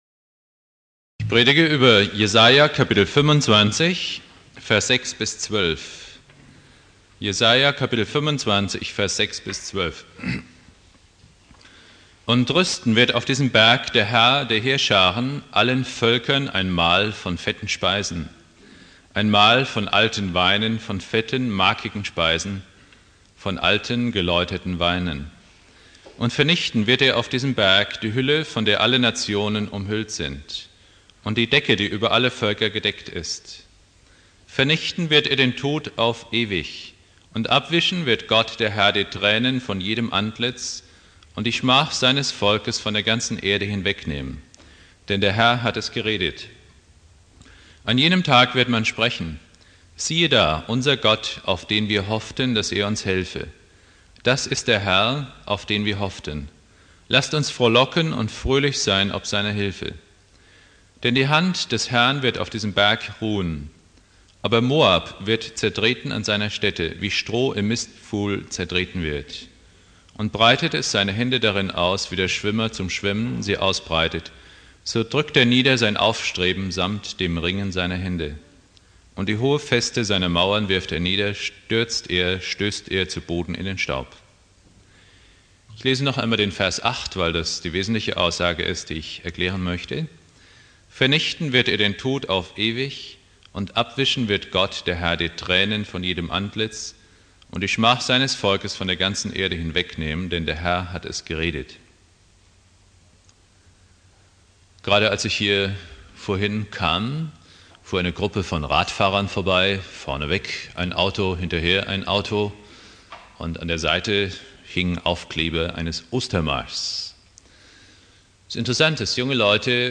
Predigt
Ostermontag